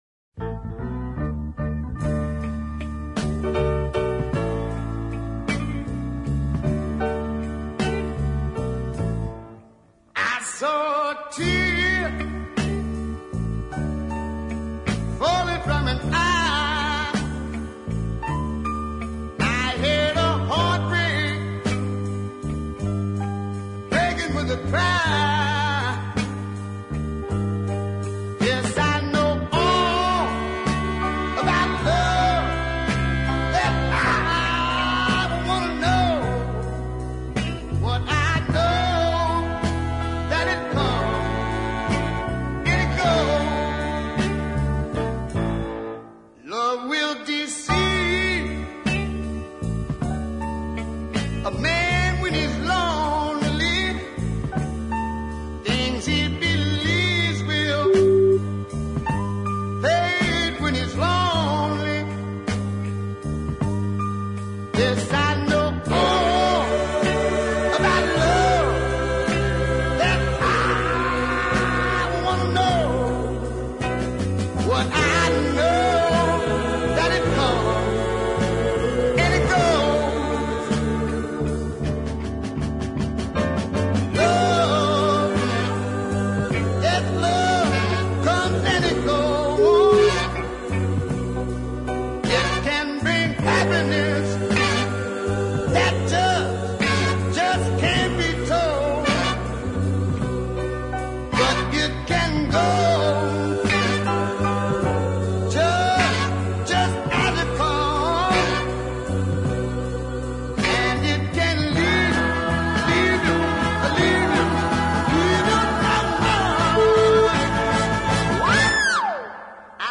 A near perfect deep soul piece.